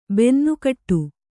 ♪ bennukaṭṭu